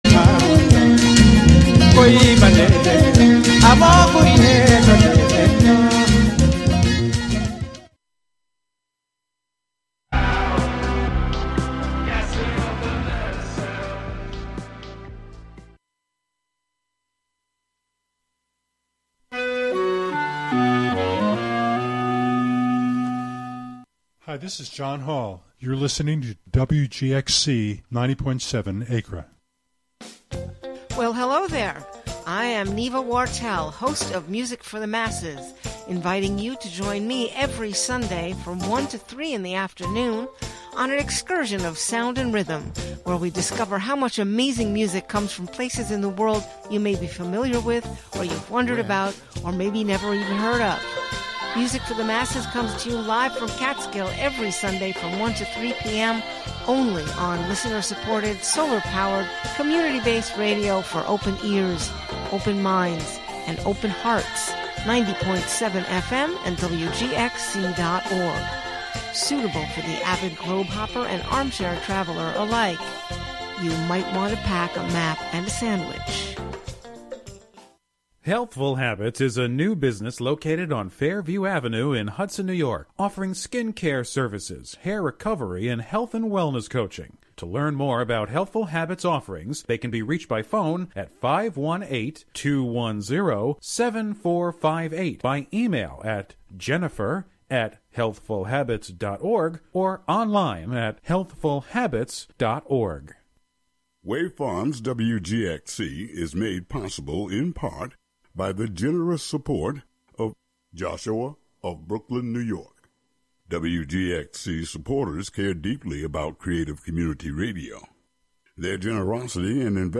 An hour of sweet gospel music